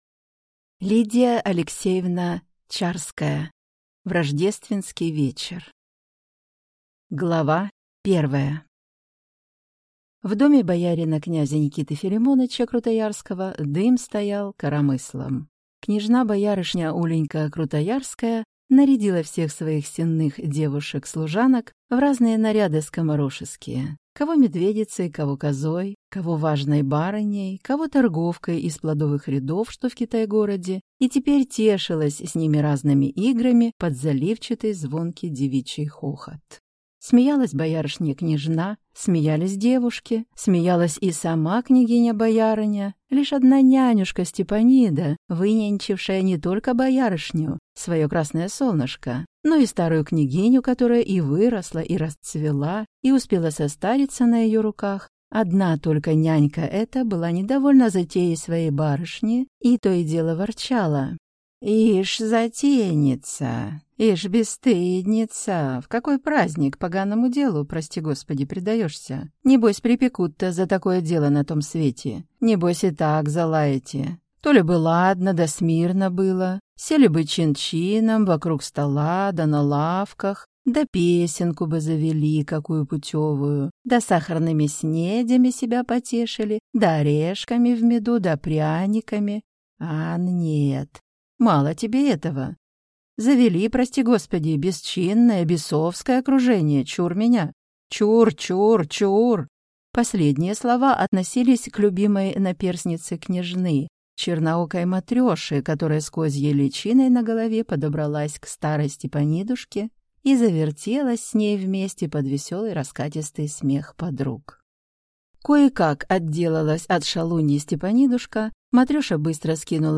Аудиокнига В рождественский вечер | Библиотека аудиокниг